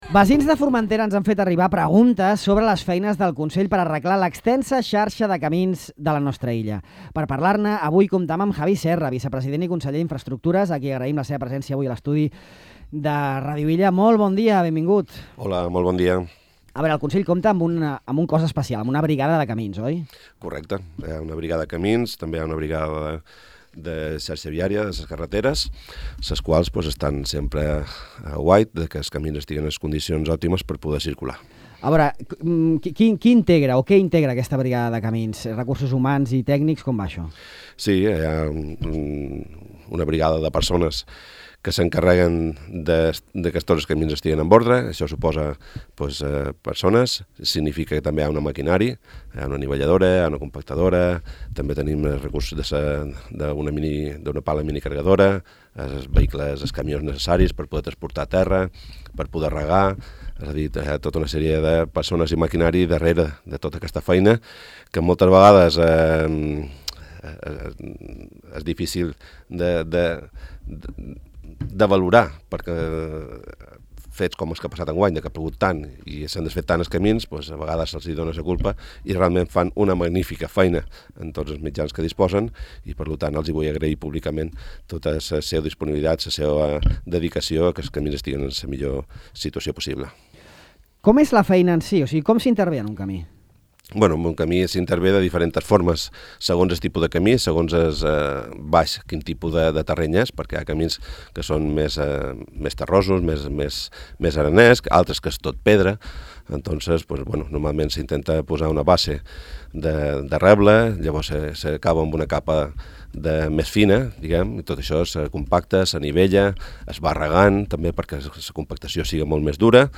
El vicepresident i conseller d’Infraestructures del Consell de Formentera, Javi Serra, ha explicat a Ràdio Illa com s’organitza la feina de la brigada de camins, un equip de persones i maquinària —anivelladora, compactadora, minicarregadora i camions— que treballa tot l’any per mantenir en bones condicions l’extensa xarxa de camins públics de l’illa.